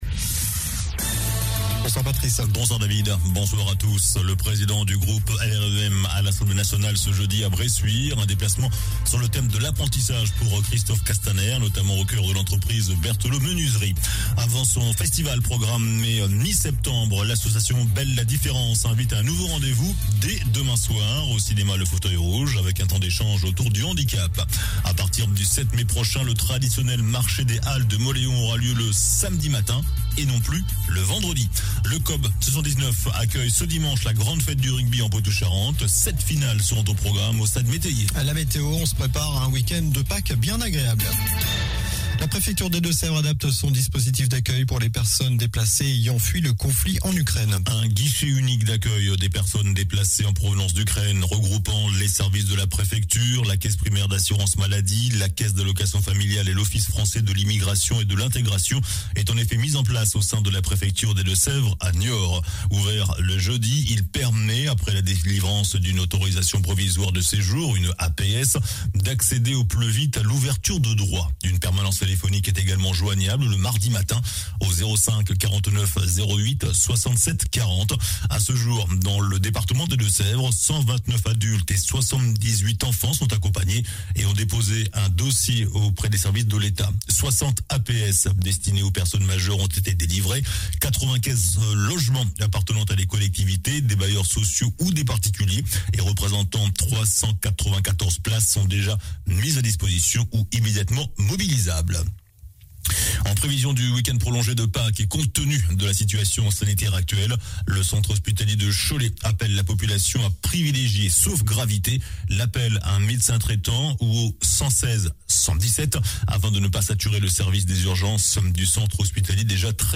JOURNAL DU JEUDI 14 AVRIL ( SOIR )